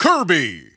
The announcer saying Kirby's name in English and Japanese releases of Super Smash Bros. Brawl.
Kirby_English_Announcer_SSBB.wav